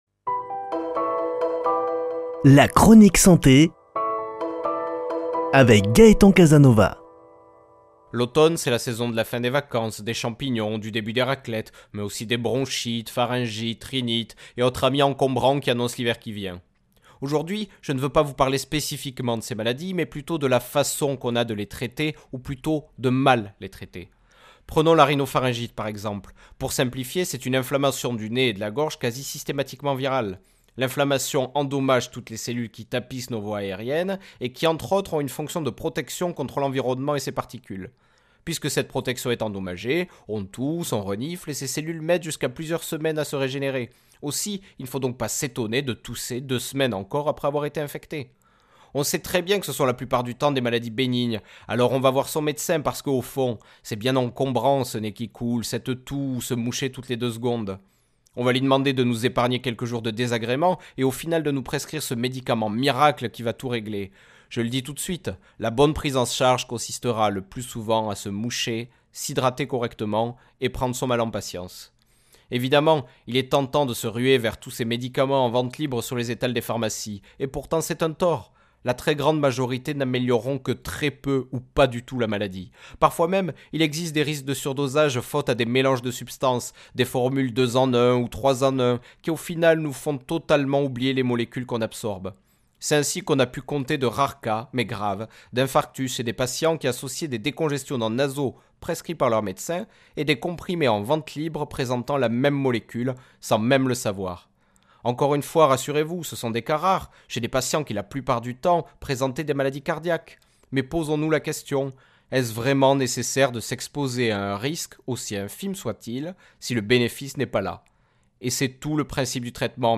Chronique santé